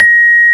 CELESTE7.WAV